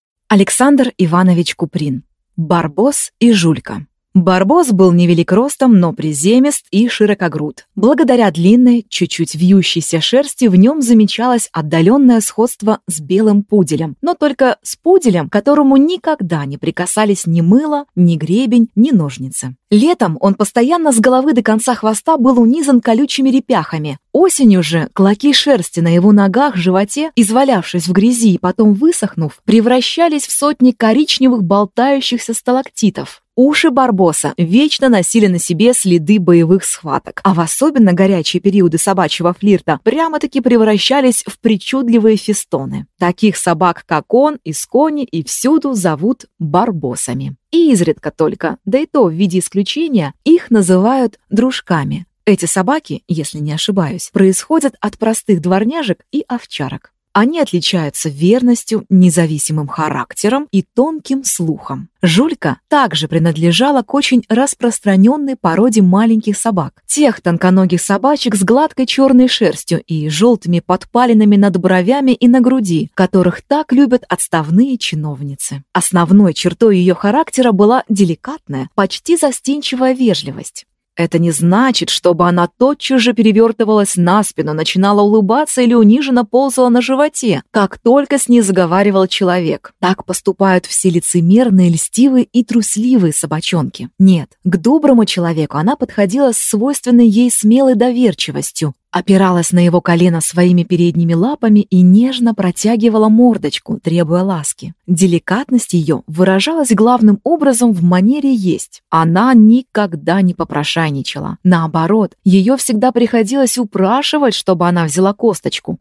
Аудиокнига Барбос и Жулька | Библиотека аудиокниг